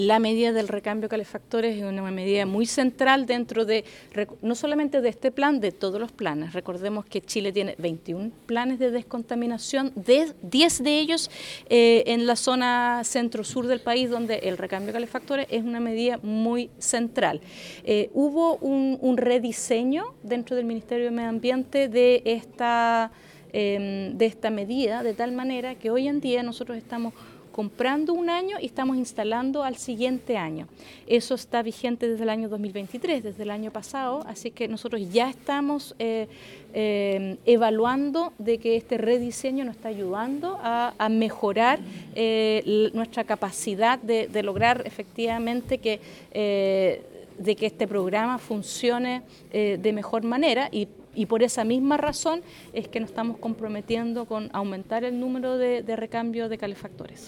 La Ministra de Medio Ambiente, María Heloisa Rojas, enfatizó en que este programa es vital para el Plan de Descontaminación del Aire que se implementará en la macrozona norte de la Región de Los Lagos, por lo que se buscará aumentar el número de calefactores a recambiar.